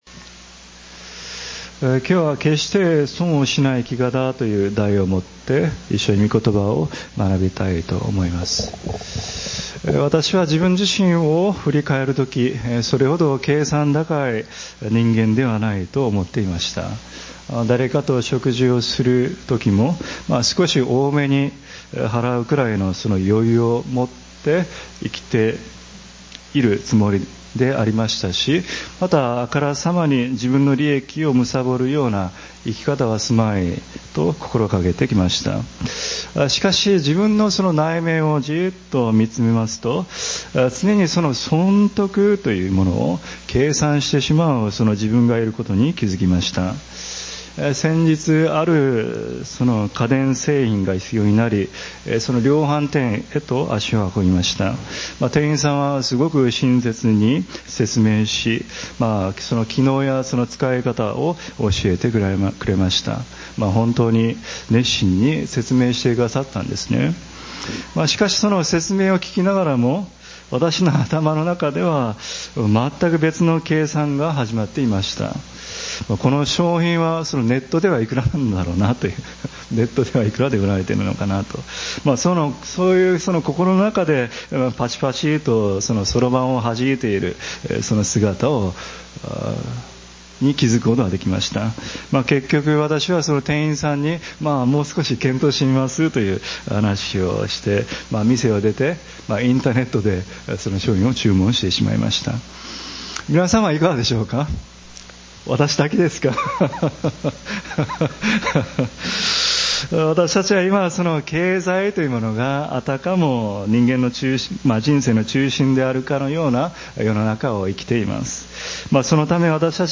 礼拝次第